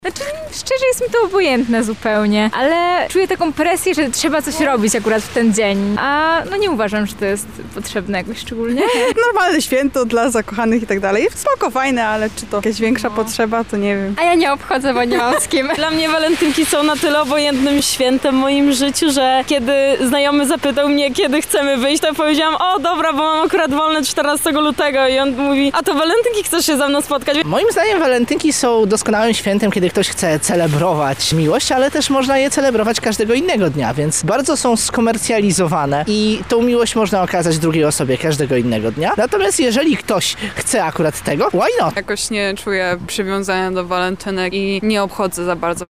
Zapytaliśmy studentów o ich nastawienie do tego dnia.
sonda